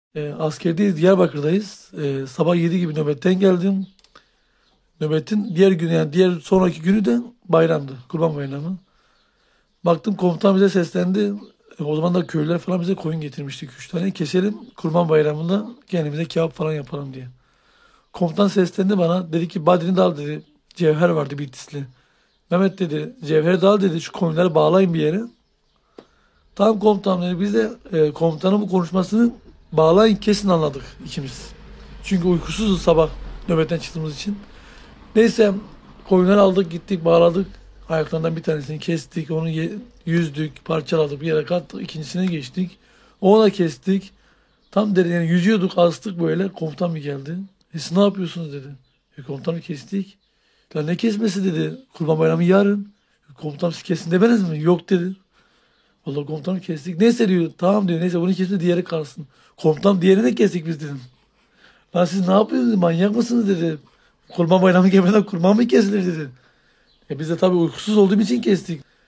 Prerequisites for this Turkish Listening Comprehension Lesson